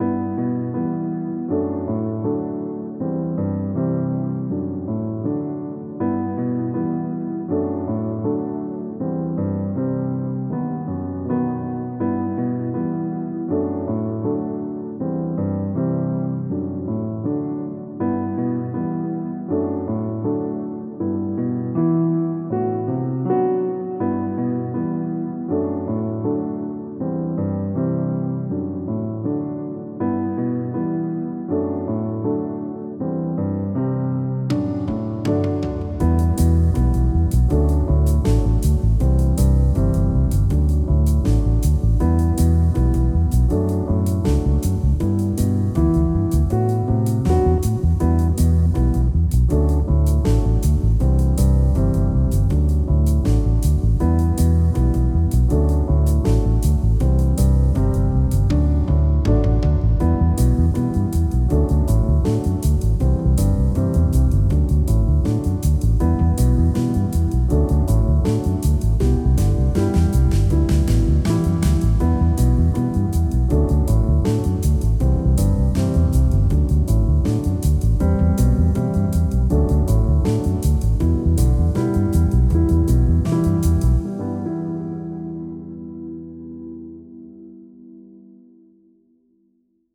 今回ピアノメインのしっとりとした曲でまとめました。
NoRegret-offvocal.mp3